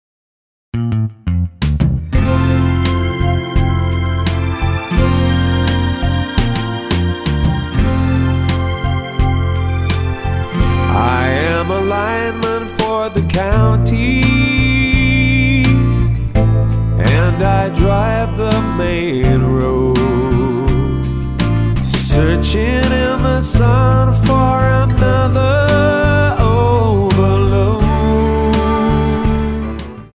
Tonart:D Multifile (kein Sofortdownload.
Die besten Playbacks Instrumentals und Karaoke Versionen .